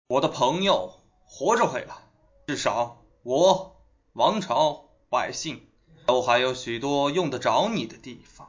大气随性